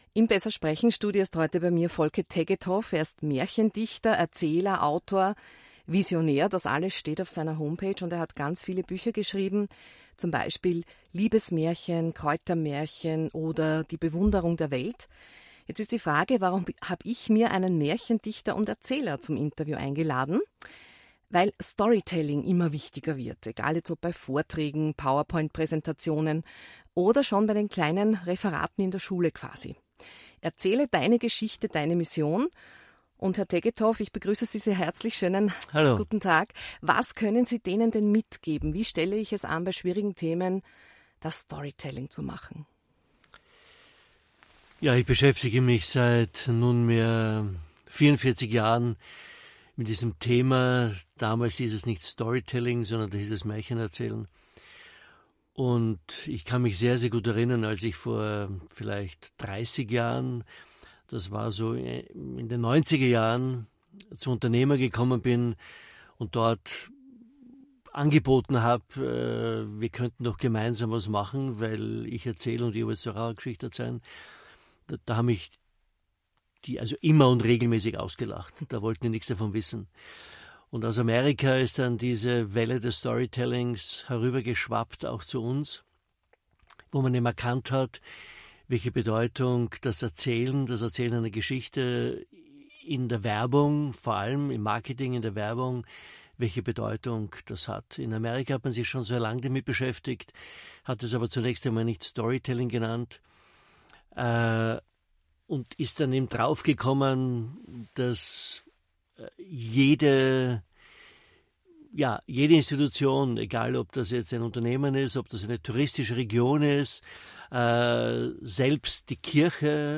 Interview mit Folke Tegetthoff